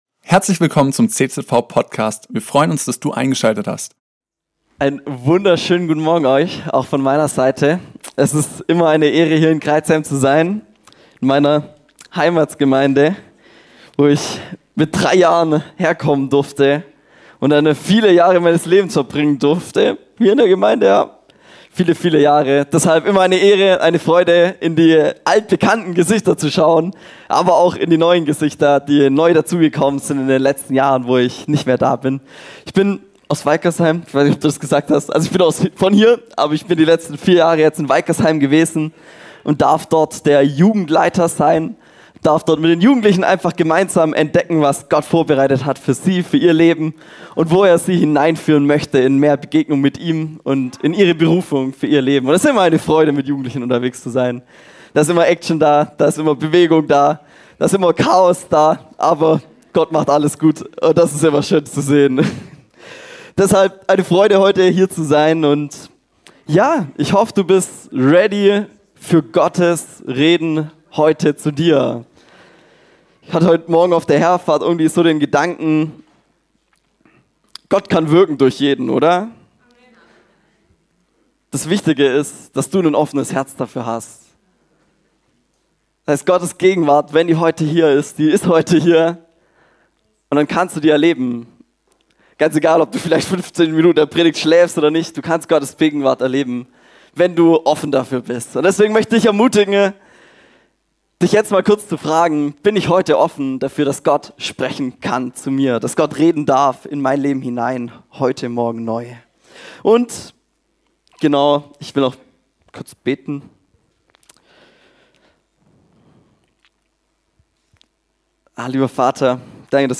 predigt darüber, den Sabbat zu ehren. Er stellte das alte Testament dem neuen Testament gegenüber und zeigt, dass es dabei viel mehr auf die Beziehung zu Gott und die innere Herzenshaltung ankommt.